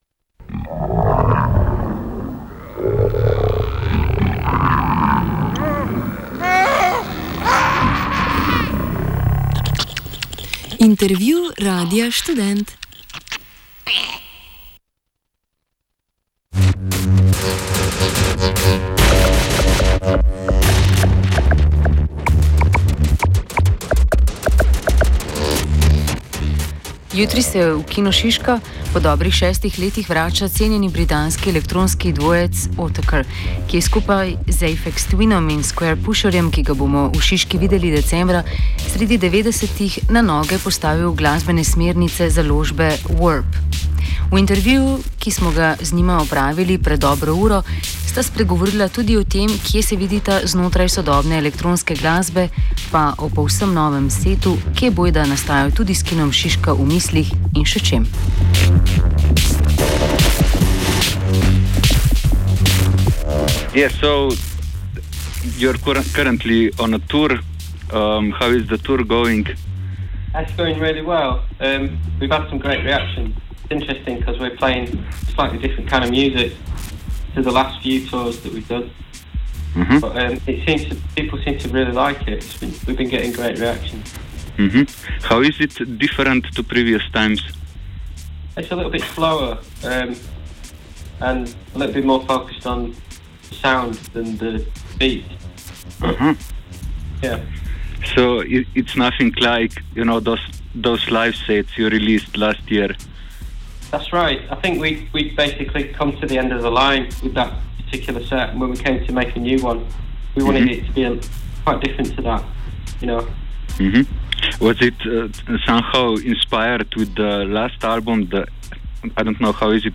Radio Student interview conducted via phone during the aeonesix tour, one day before their Nov 5 2016 gig in Ljubljana.